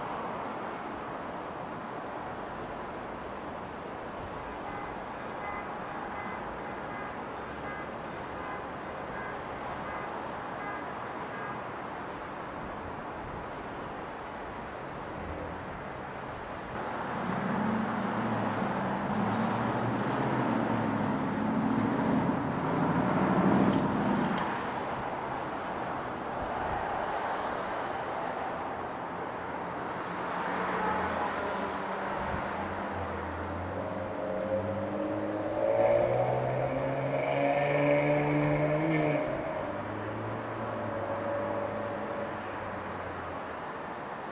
amb_city.wav